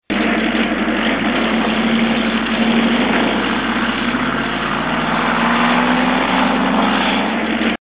autofaehrt.mp3